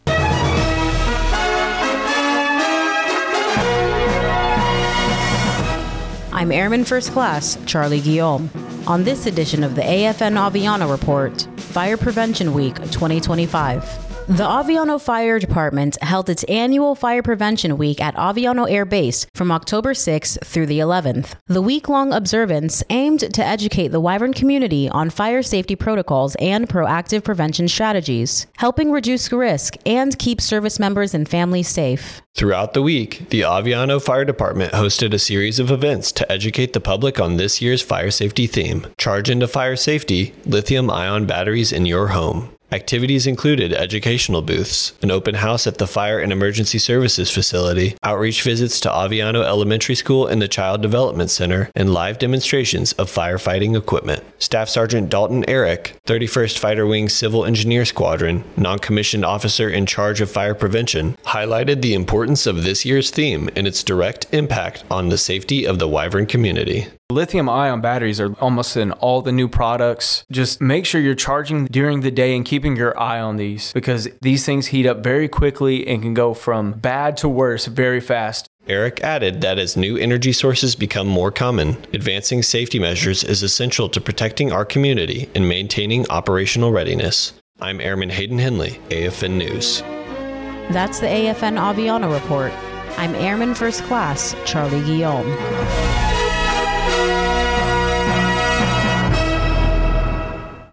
AFN Aviano Radio News: Fire Prevention Week 2025
American Forces Network Aviano radio news reports on Fire Prevention Week at Aviano Air Base, Italy, Oct. 6-11, 2025. The week-long observance aimed to educate the Wyvern community on fire safety protocols and proactive prevention strategies, helping reduce risk and keep service members and families safe.